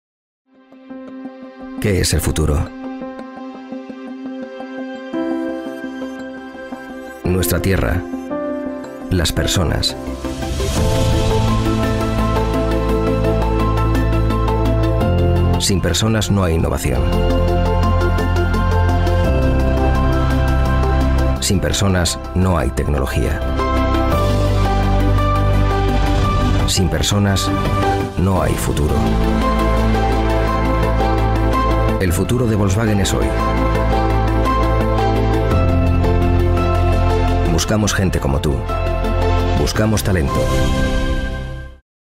European Spanish Voice over.
kastilisch
Sprechprobe: Werbung (Muttersprache):
My voice is warm, smooth, friendly, authoritative, clear, enthusiastic and most importantly, believable. I can be warm and deep but also energetic and fresh.